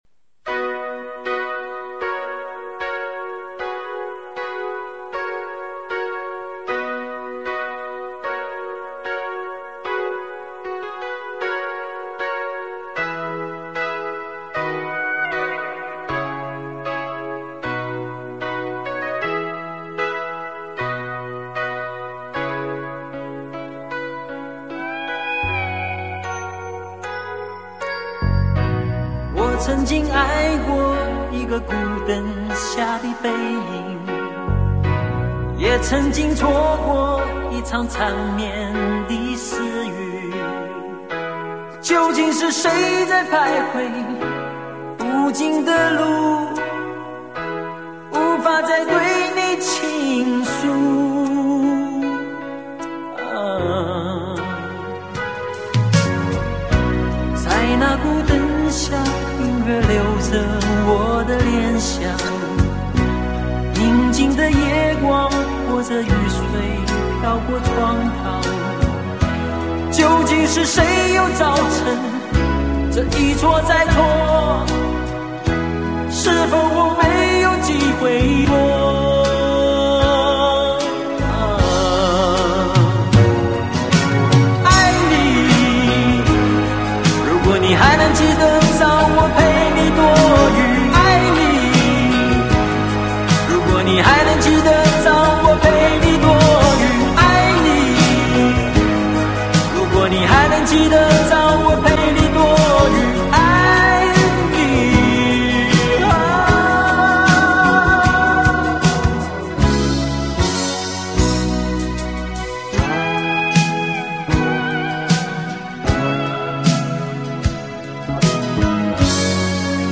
悦耳流畅的钢琴前奏很容易的让人回到初恋的年代。